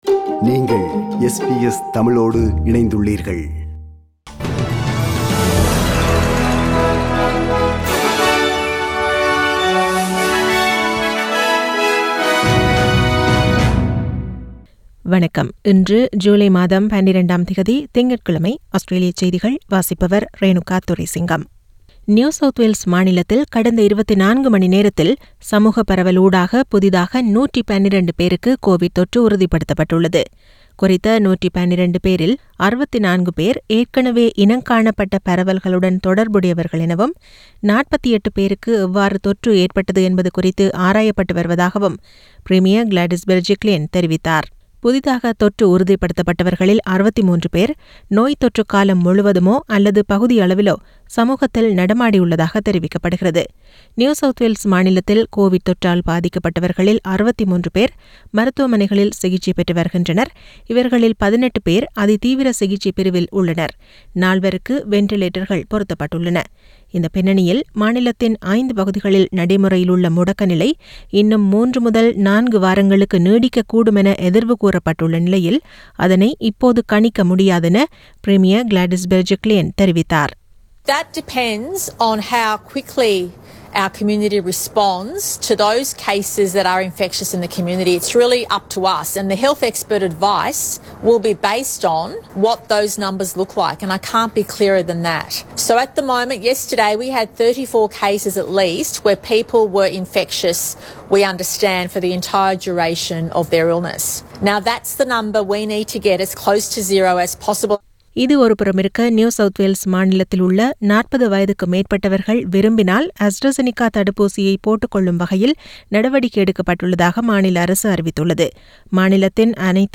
SBS தமிழ் ஒலிபரப்பின் இன்றைய (திங்கட்கிழமை 12/07/2021) ஆஸ்திரேலியா குறித்த செய்திகள்.